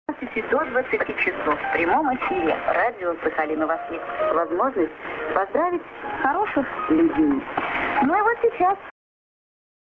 ->ID(women)->　USB R.Sakharinsk(Radio Rossii)